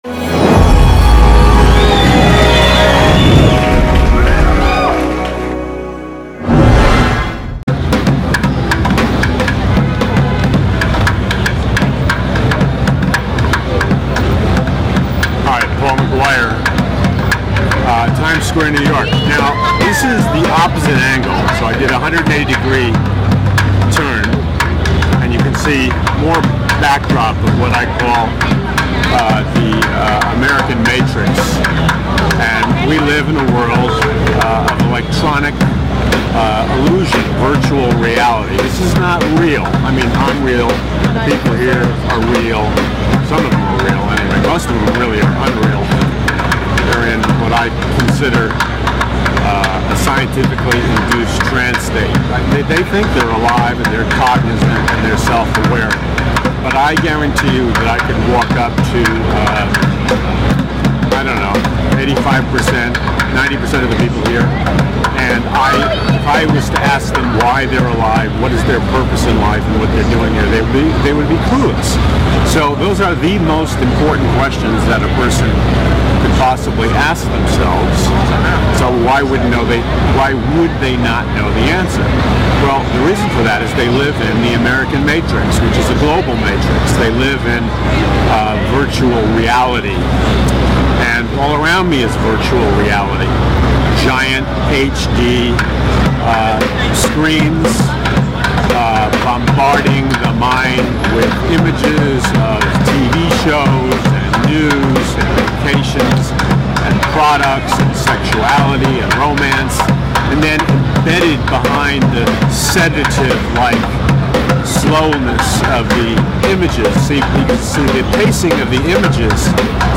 💥 LIVE FROM TIME SQUARE! THE AMERICAN MATRIX FINAL FRONTIER